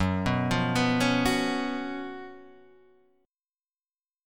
GbM7sus4 chord